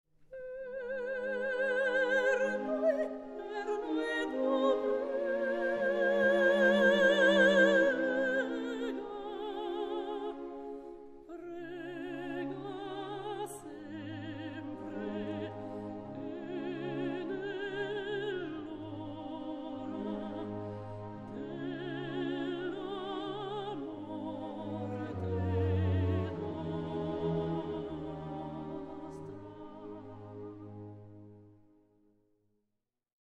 Super Audio CD